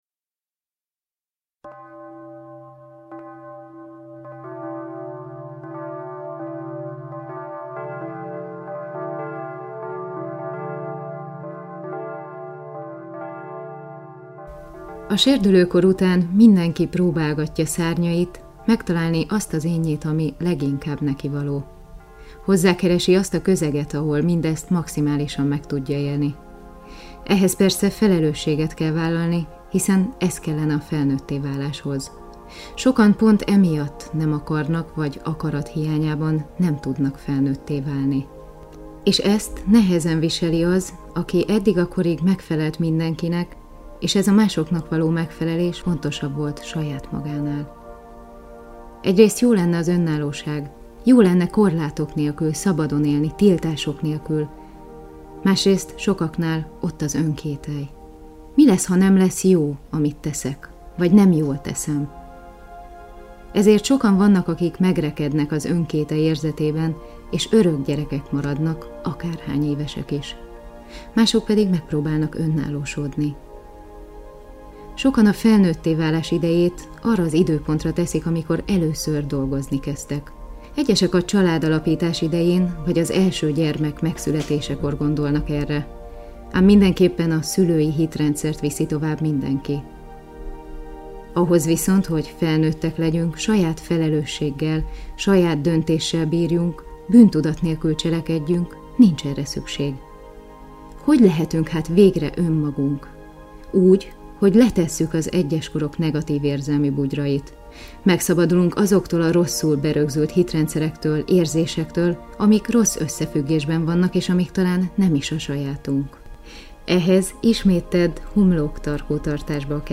Mindegyik cd-n Szepes Mária előszava hallható! fenyhaz